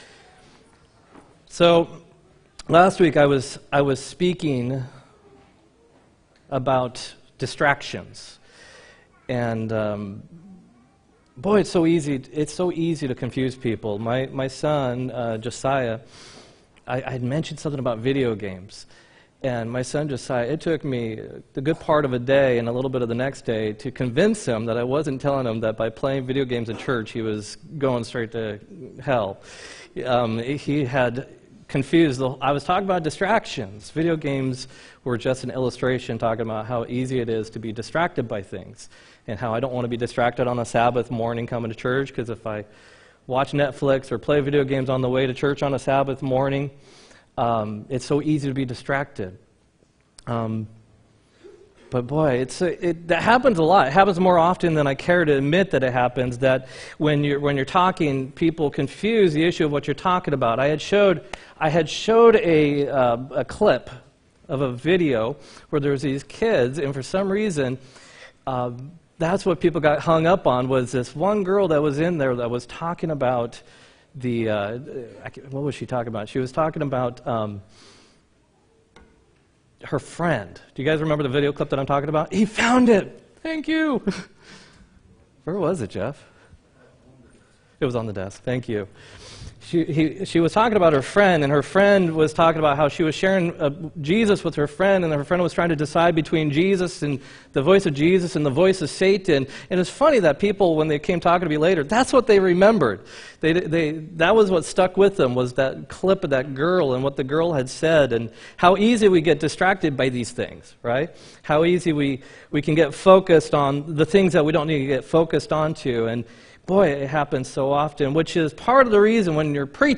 9-15-18 sermon